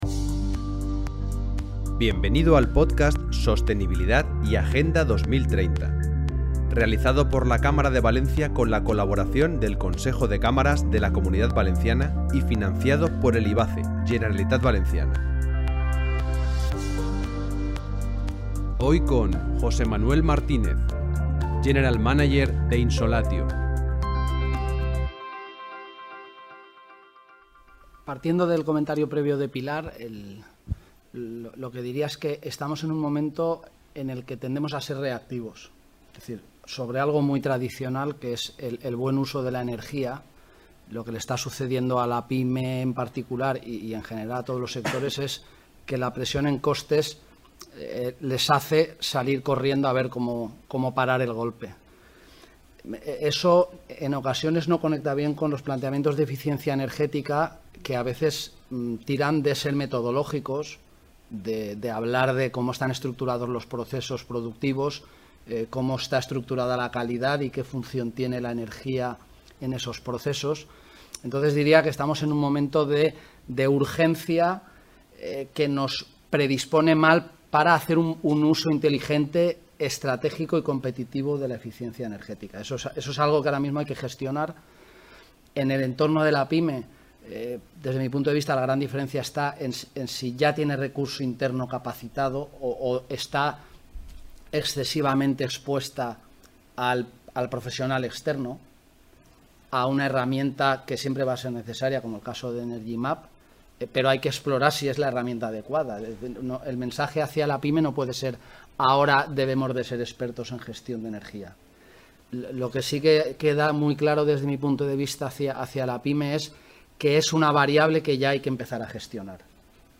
Con el podcast “La revolución 4.0” pretendemos realizar una foto desde dentro de las propias compañías, entrevistando a los CEO, directivos y/o responsables de la transformación digital de un gran número de empresas de Valencia, algunas de la Comunidad Valencia y también del territorio nacional.